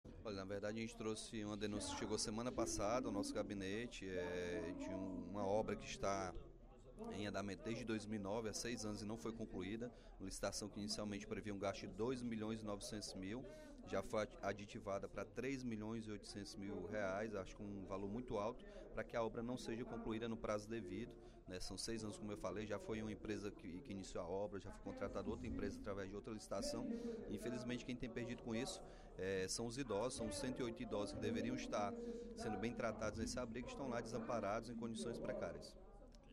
O deputado Capitão Wagner (PR) abordou novamente, nesta quarta-feira (02/09), no primeiro expediente da sessão plenária, a situação do abrigo dos idosos localizado na rua Olavo Bilac, 2060, no bairro São Gerardo, em Fortaleza.